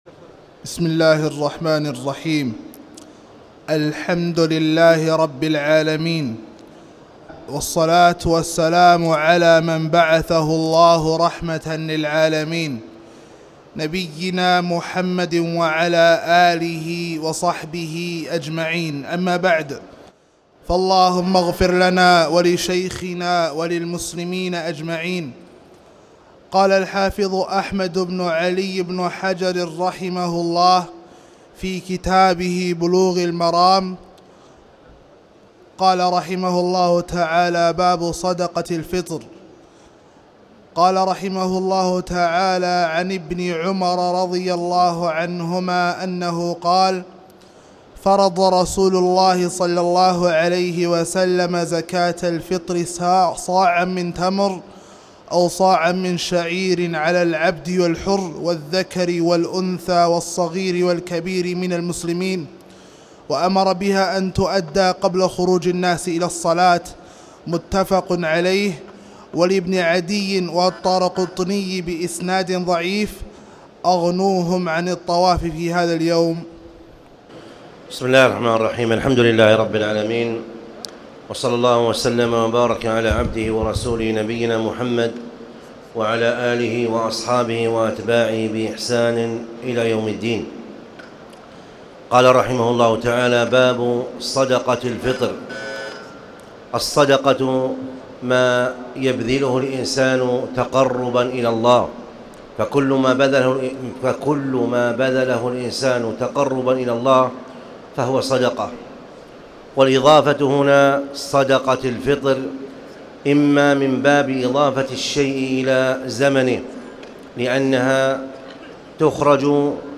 تاريخ النشر ٢٨ رمضان ١٤٣٨ هـ المكان: المسجد الحرام الشيخ